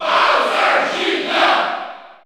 Category: Crowd cheers (SSBU) You cannot overwrite this file.
Bowser_Jr._Cheer_French_NTSC_SSBU.ogg